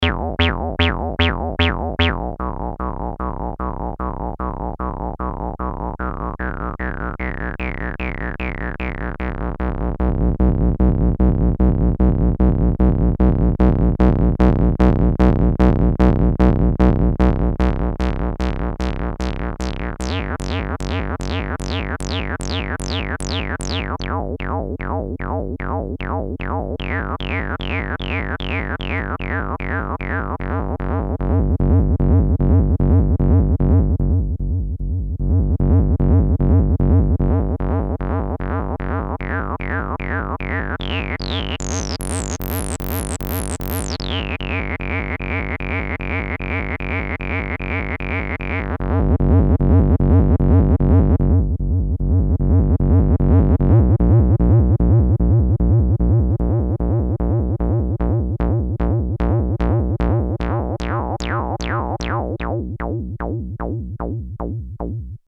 Bassbot solo 1